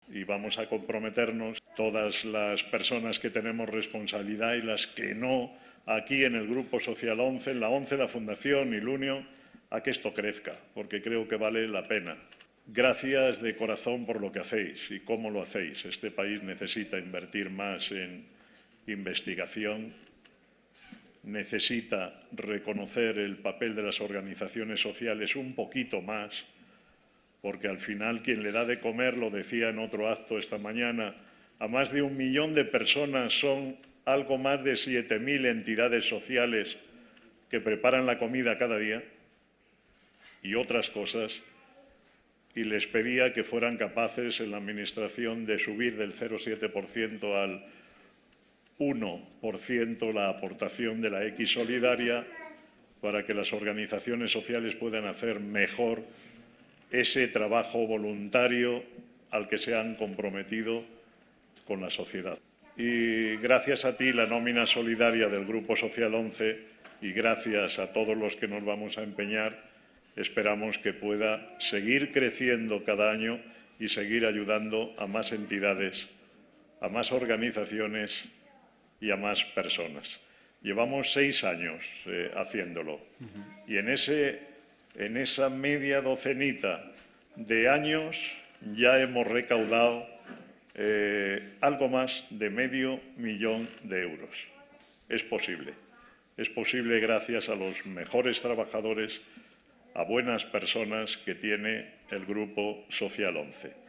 El acto de entrega de los cheques solidarios del programa ‘Gracias A Ti’ reunió a trabajadores de todas las áreas ejecutivas del Grupo Social ONCE (ONCE, Fundación ONCE e ILUNION) en un acto celebrado en el Palacete de los Duques de Pastrana en Madrid el pasado 10 de febrero, que también pudo seguirse en streaming.